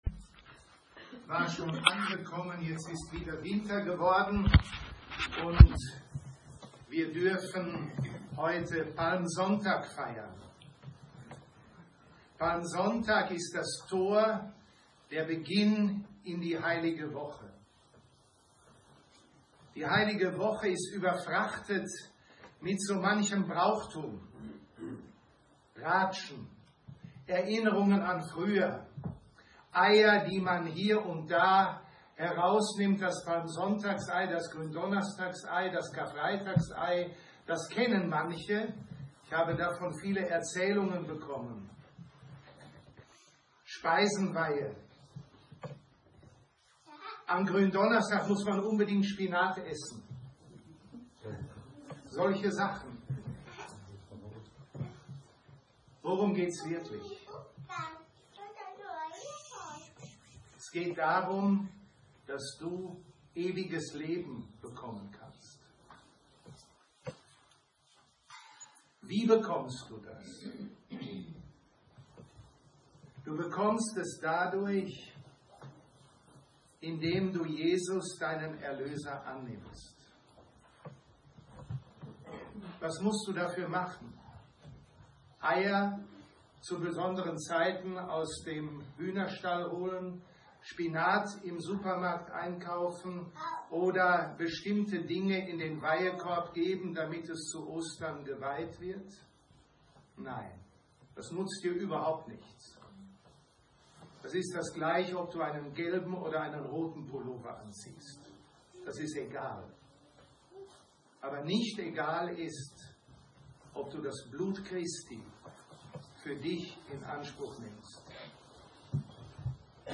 Audioaufnahme bearbeitet
palmsonntag2026.mp3